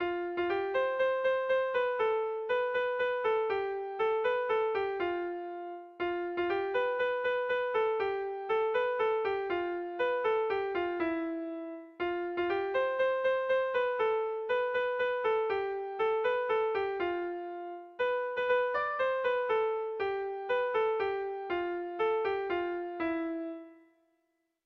Dantzakoa